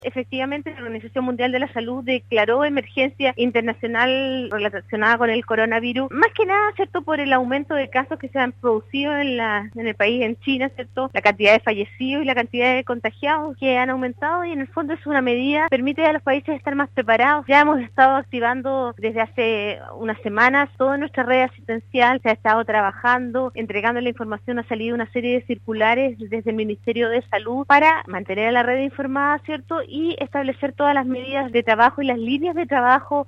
En conversación con Radio Sago, Marcela Cárdenas, seremi (s) salud en la región de Los Lagos se refirió a la enfermedad del Coronavirus o también conocida como neumonía de Wuhan que ha generado alarma a nivel mundial tras haber cobrado la vida de más de 200 personas en China.